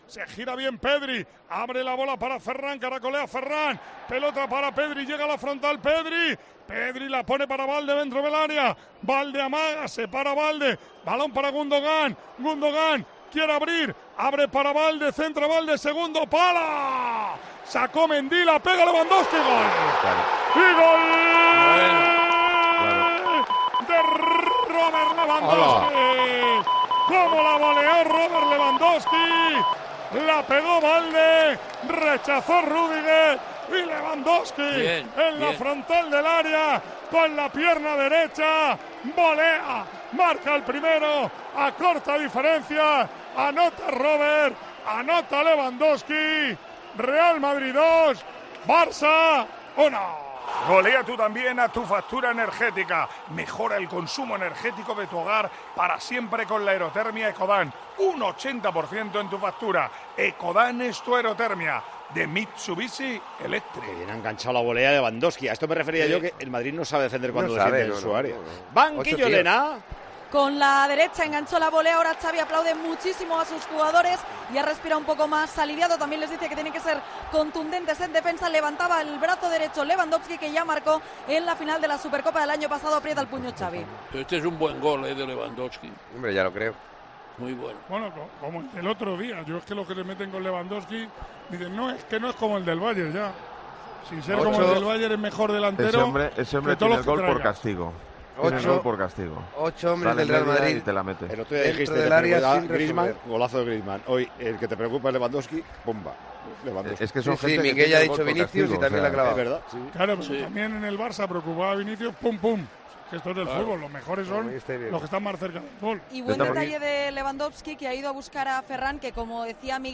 ESCUCHA LA RETRANSMISIÓN DE LA FINAL DE LA SUPERCOPA DE ESPAÑA REAL MADRID - BARCELONA EN TIEMPO DE JUEGO